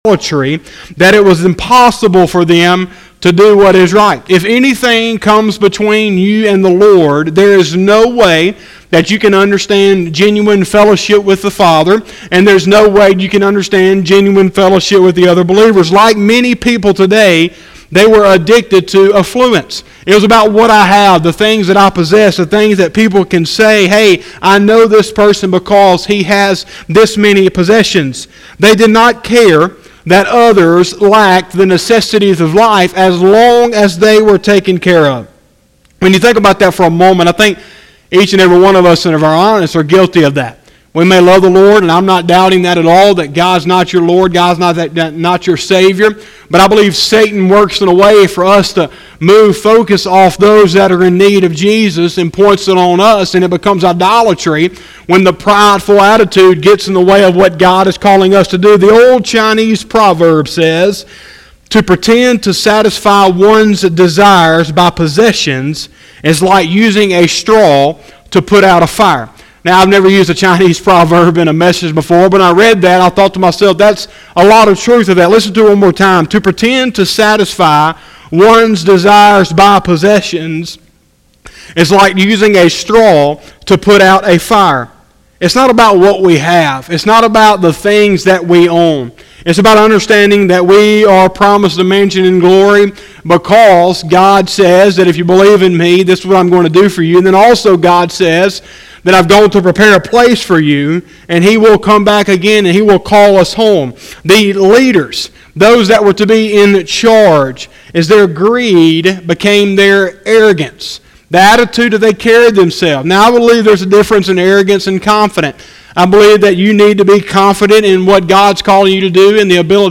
02/05/2020 – Wednesday Evening Service